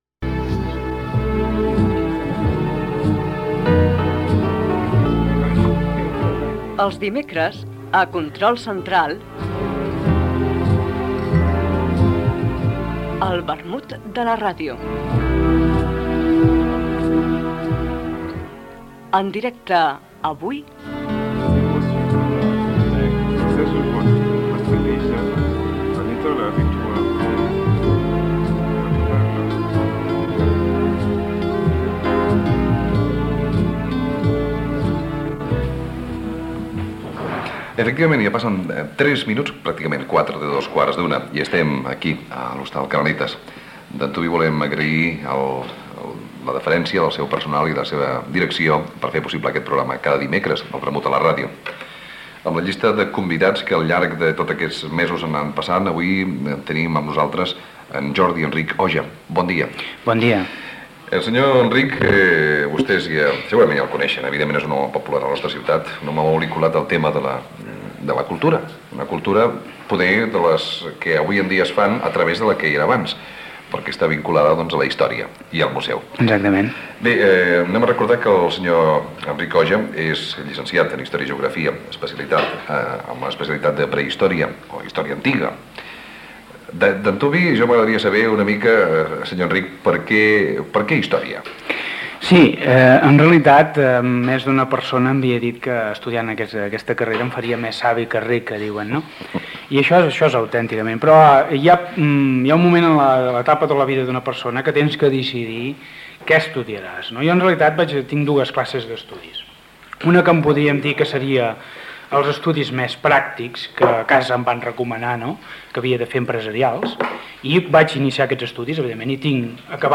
Espai fet en directe des de l'Hostal Canaletas d'Igualda.
Entreteniment